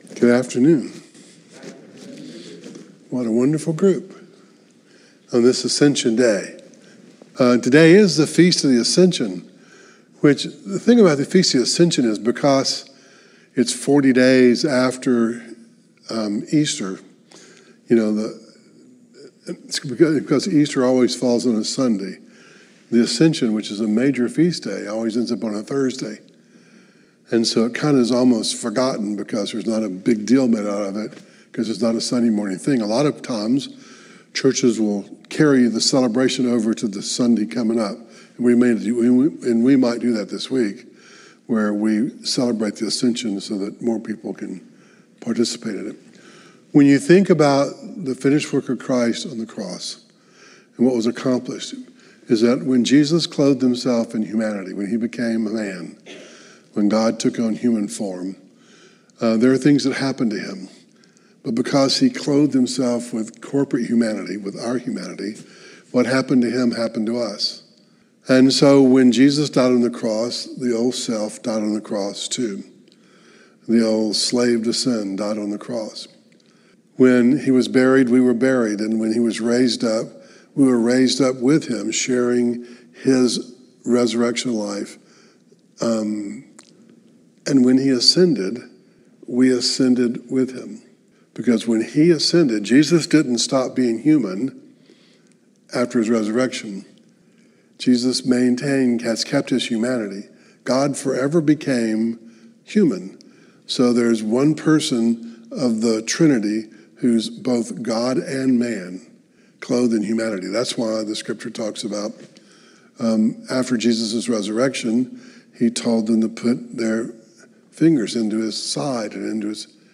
Luke 24:45-53 Service Type: Devotional